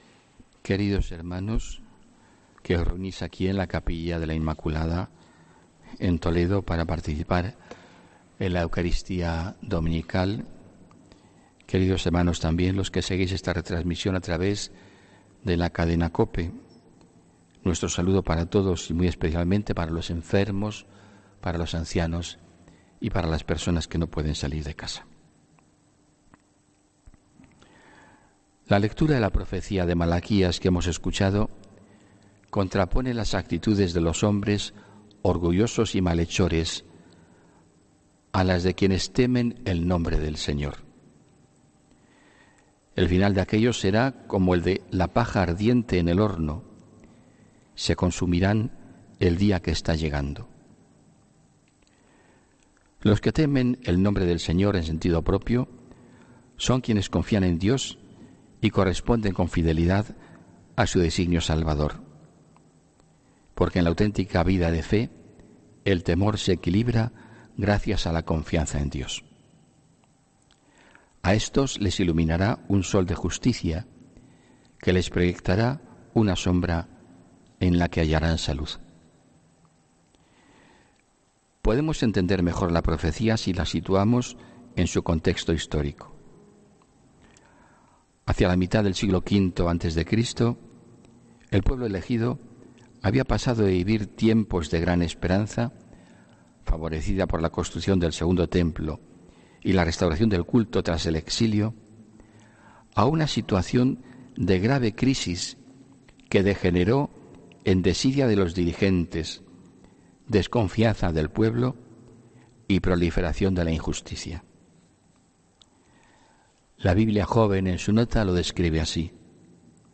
HOMILÍA 17 NOVIEMBRE